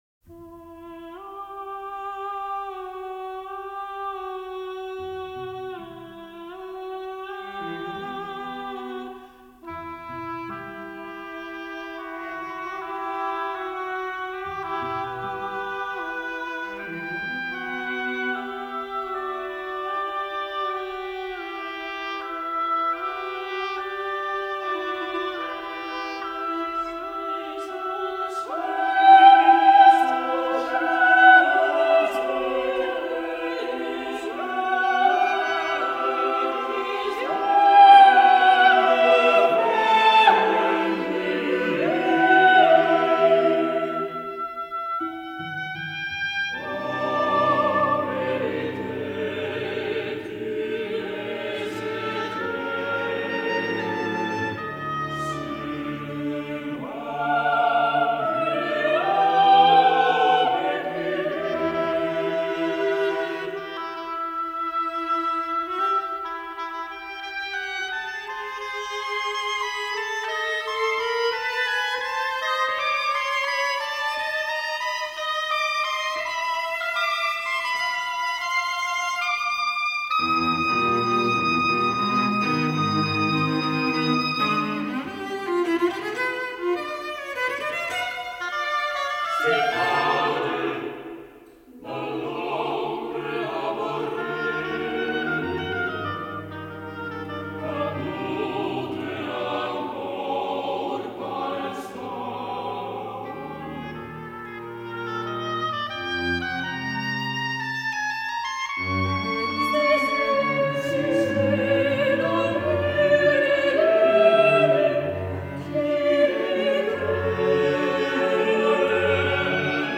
Vokālā mūzika